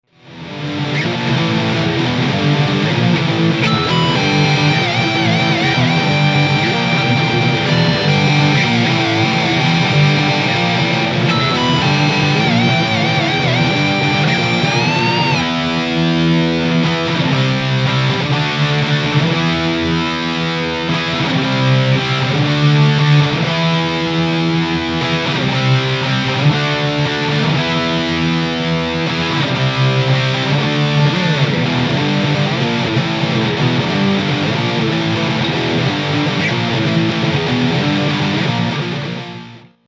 Marshall AVT50でサンプルサウンドを作ってみました。
使用ギターは、EDWARDS E-LK-100DK（Laputa kouichiモデル）
EMG81、89です。